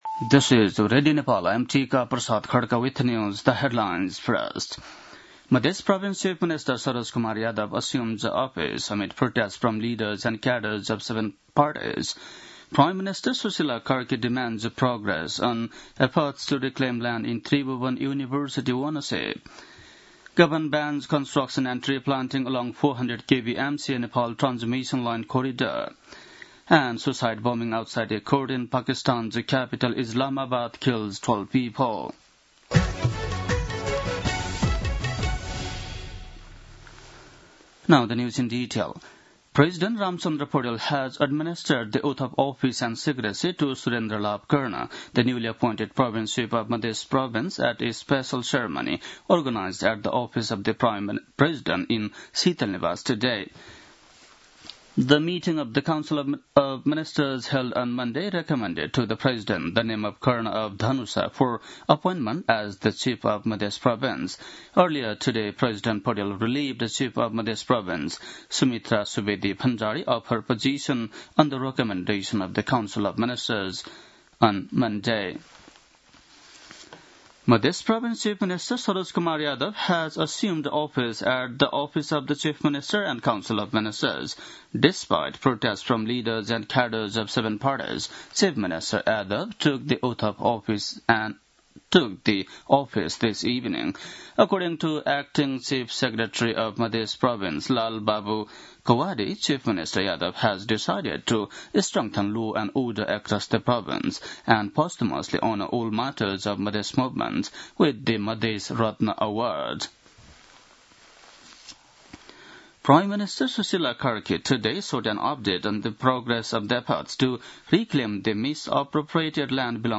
An online outlet of Nepal's national radio broadcaster
बेलुकी ८ बजेको अङ्ग्रेजी समाचार : २४ कार्तिक , २०८२